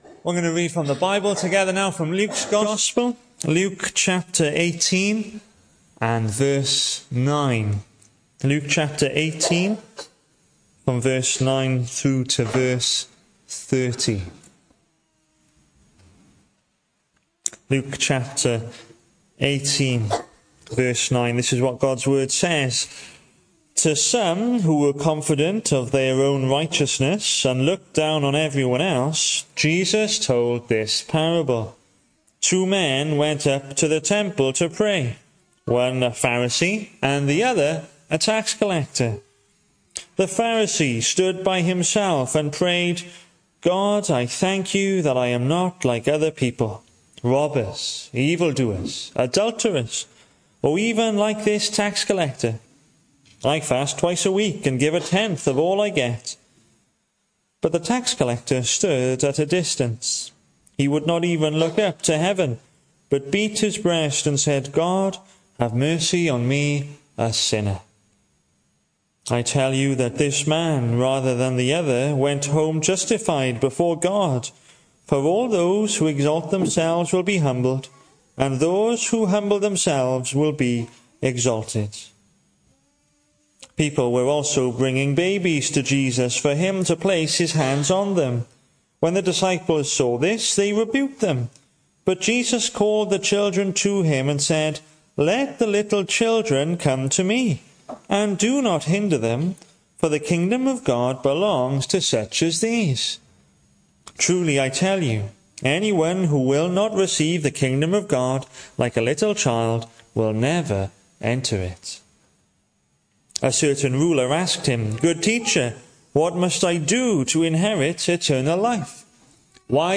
Hello and welcome to Bethel Evangelical Church in Gorseinon and thank you for checking out this weeks sermon recordings. The 26th of January saw us host our Sunday morning service from the church building, with a livestream available via Facebook.